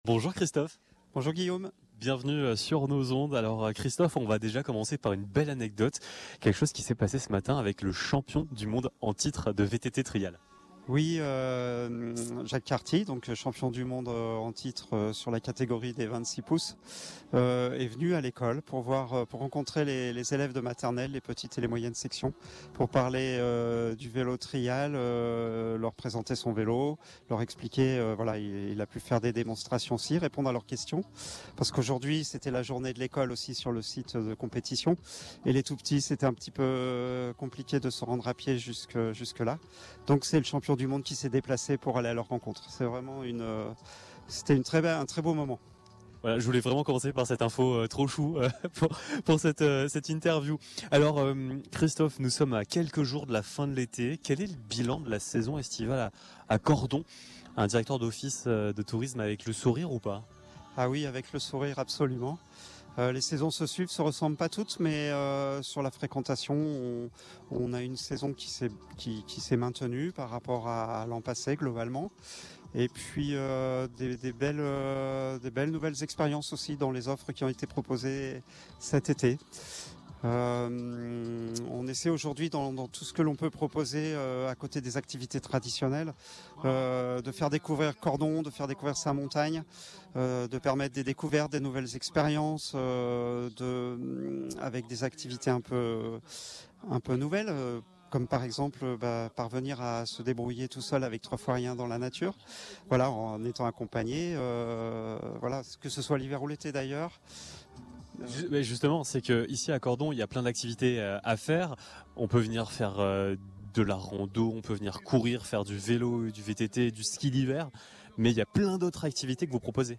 À l’occasion de la Coupe du Monde de VTT Trial, nous étions en direct de Cordon pour une émission spéciale en direct du cœur de l’événement.
Interview